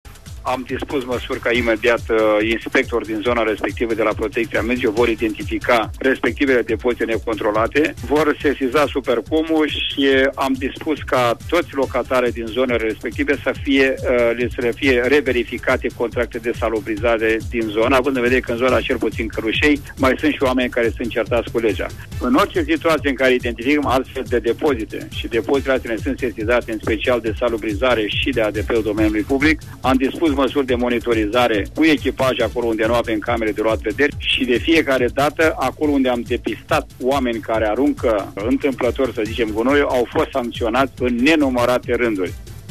Directorul politiei locale sector 2, Aurel Dobrila ne-a declarat